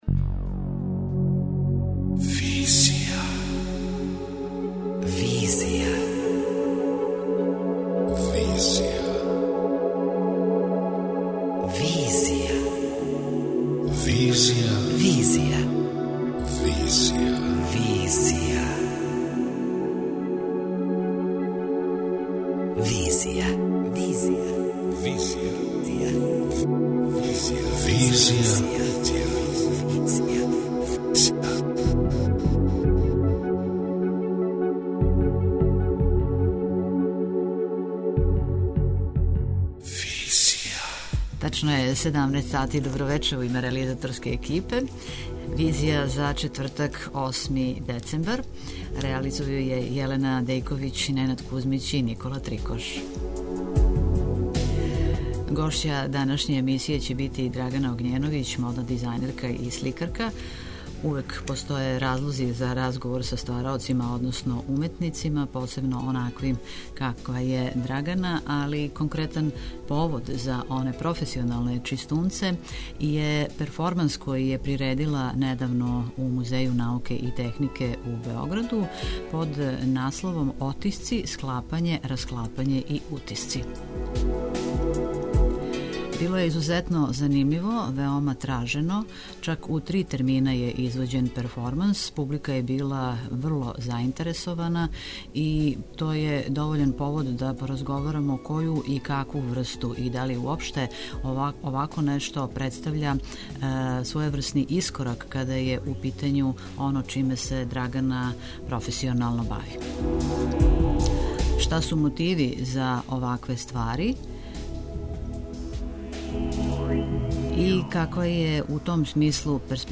преузми : 27.77 MB Визија Autor: Београд 202 Социо-културолошки магазин, који прати савремене друштвене феномене.